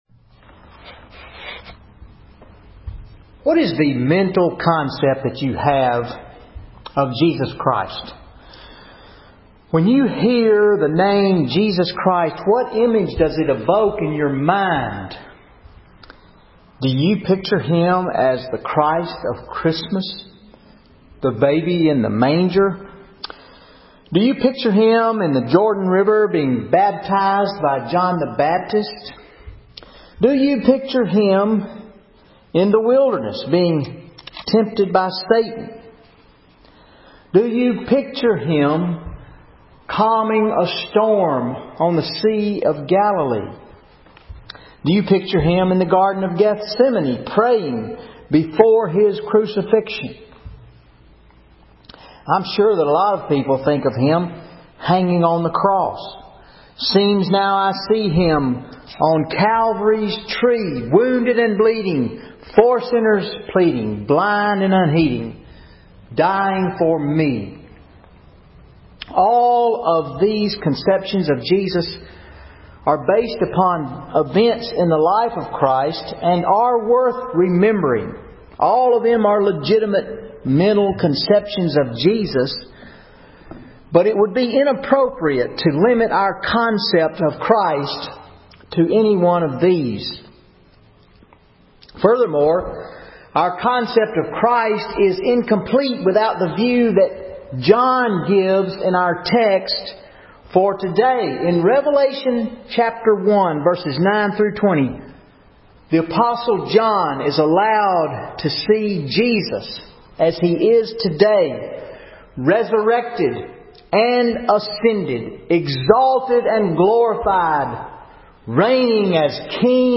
0 Comments VN810136_converted Sermon Audio Previous post Mark 10:32-45 What is True Greatness in the Eyes of Jesus?